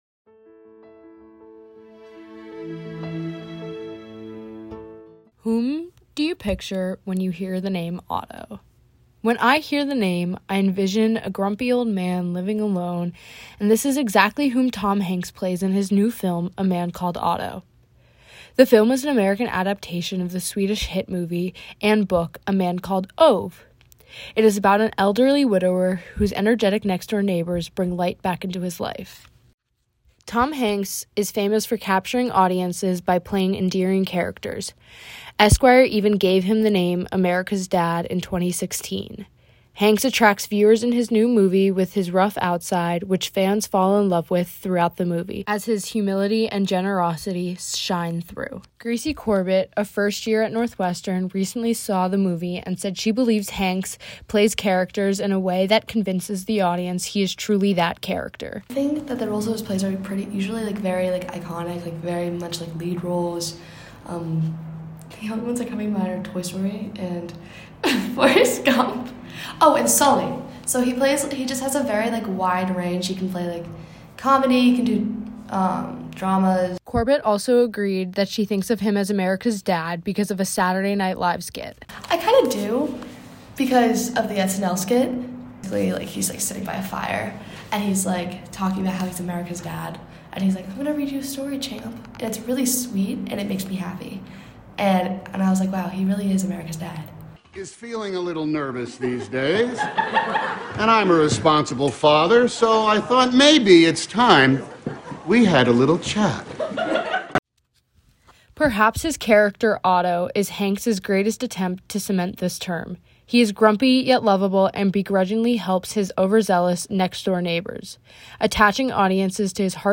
Tom Hanks stars in A Man Called Otto, the American adaption of the Swedish novel and film. Northwestern University students share their reactions to the movie.